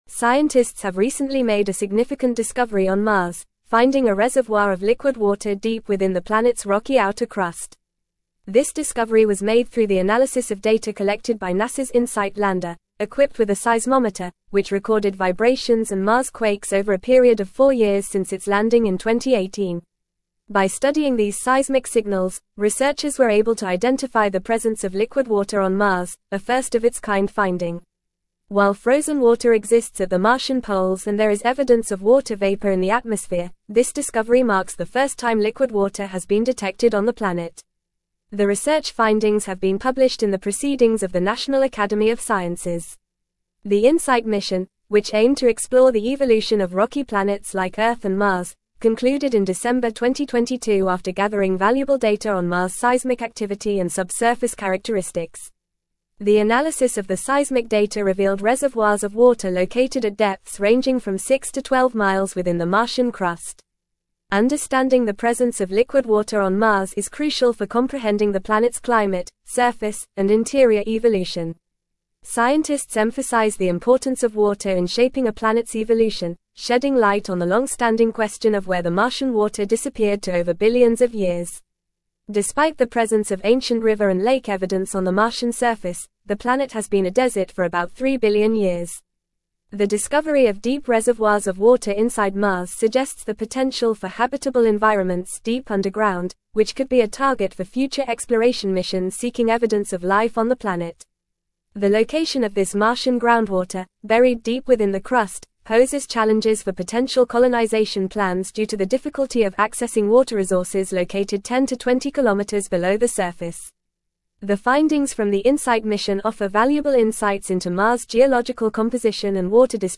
Fast
English-Newsroom-Advanced-FAST-Reading-Discovery-of-Liquid-Water-Reservoir-on-Mars-Revealed.mp3